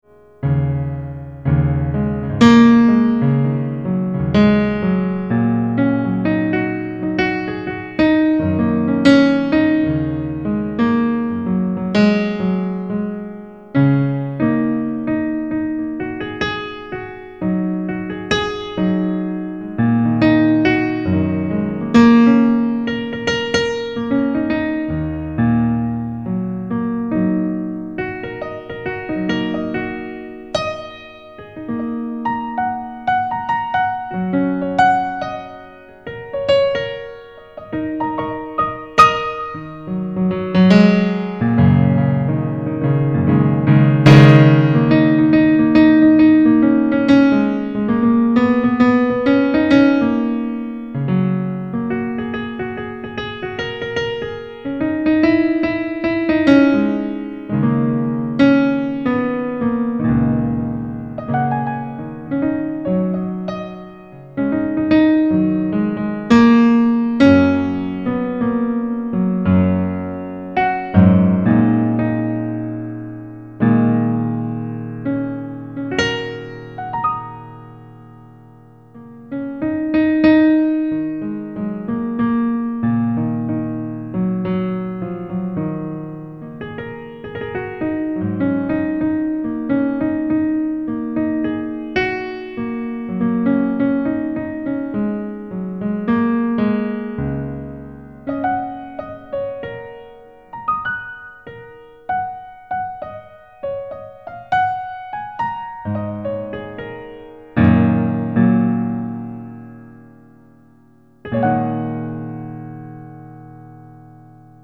כשמנגנים רק על השחורים מקבלים דו דיאז פנטטוני מז’ורי.
היי חברים אתמול היה לי משעמם אז ישבתי על האורגן וניגנתי רק על השחורים ללא תווים או אקורדים ויצא משהוא חמוד מה דעתכם?